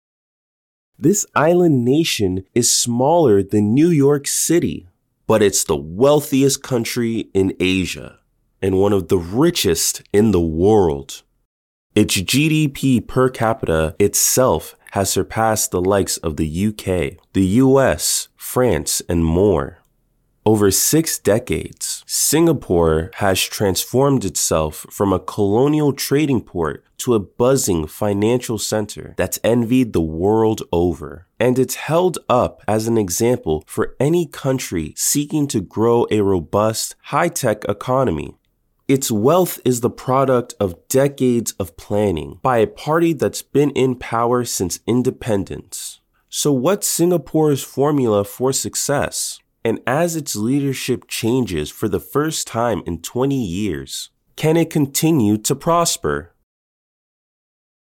Friendly and energetic individual that brings life to all narrations!
Young Adult